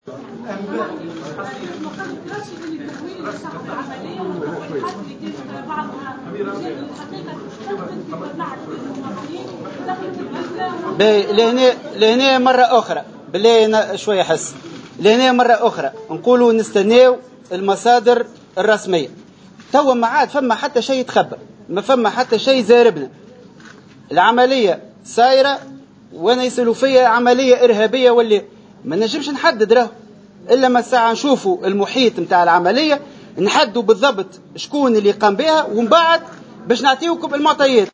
في ندوة صحفية